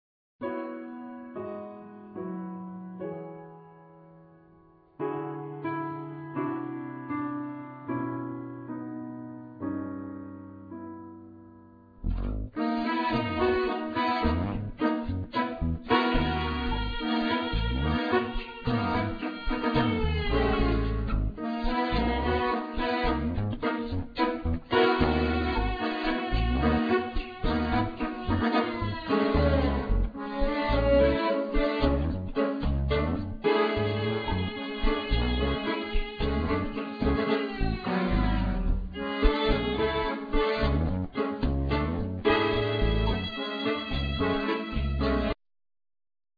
Accordeon,Vocals
Violin,Viola
Organ,Grand Piano,Pianet,Synthesizer,Vocals
Electric Basse,Bow,Darbuka,Guiro
Vibraphone,Marimba,Glockenspiel,Percussion
Drums,Bongos and Blocks